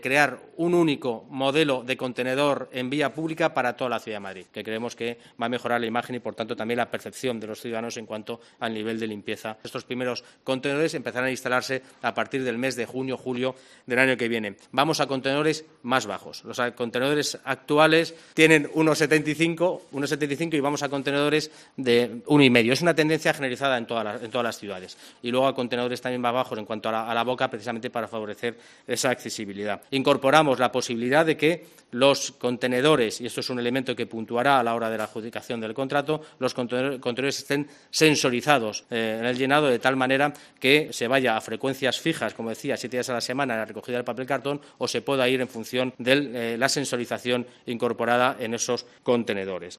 Borja Carabante, Delegado de Movilidad y Medio Ambiente explica los nuevos contenedores de basura de Madrid